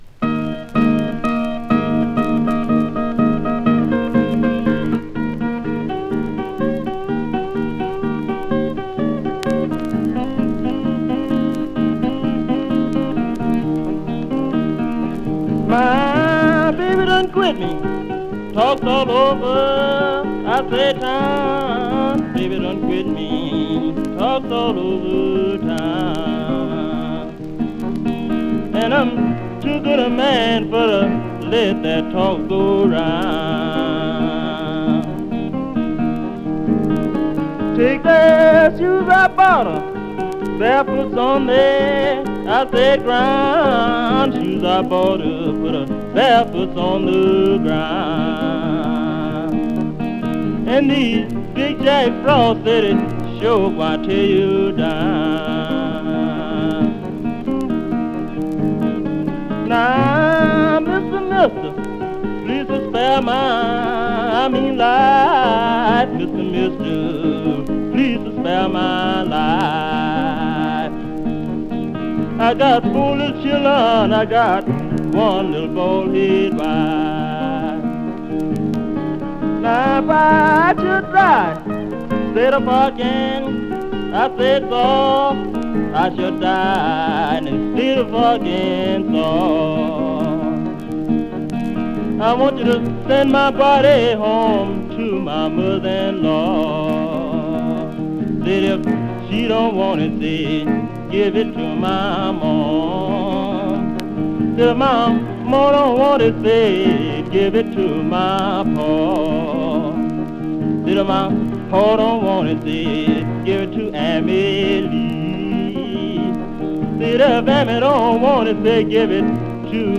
1927-31までにアラバマ州に残されたblues/folkを集めたオムニバス盤!